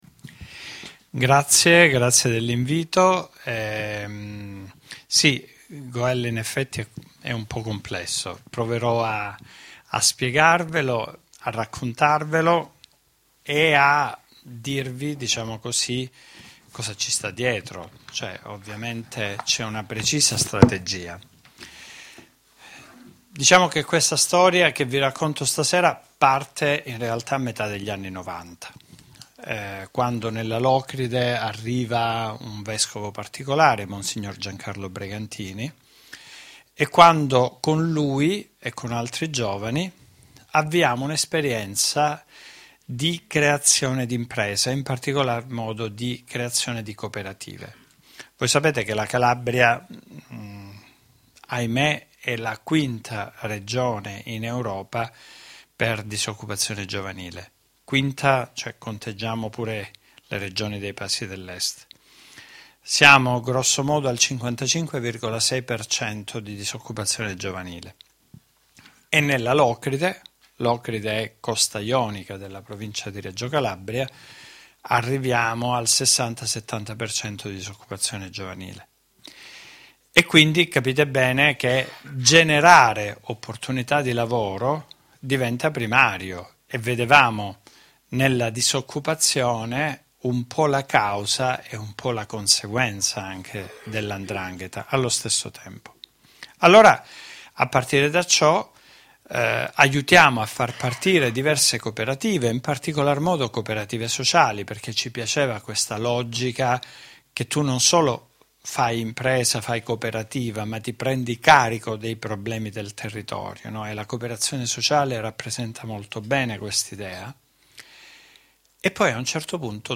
IN NOME DI… STORIE E RITRATTI DI DONNE E UOMINI CONTRO MAFIA E CORRUZIONE Seconda lezione: In nome della libertà d’impresa – Scuola Antonino Caponnetto